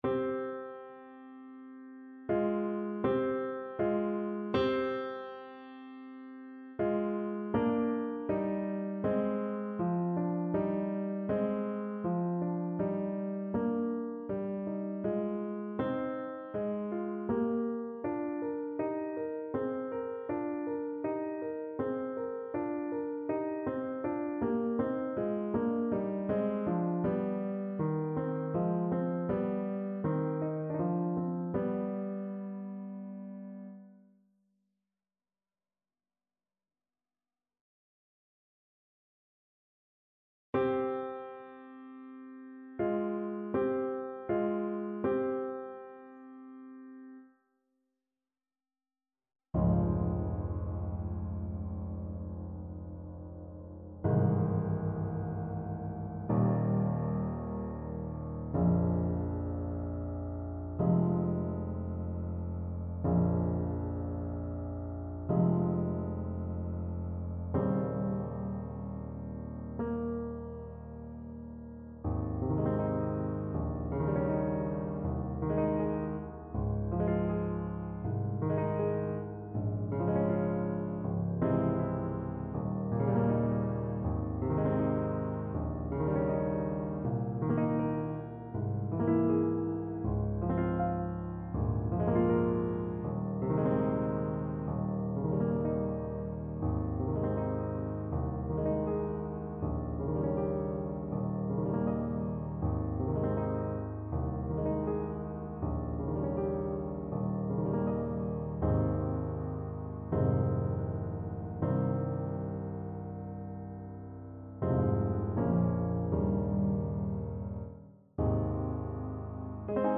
Alto Saxophone version
3/4 (View more 3/4 Music)
Andantino = c.80 (View more music marked Andantino)
Ab4-C6
Classical (View more Classical Saxophone Music)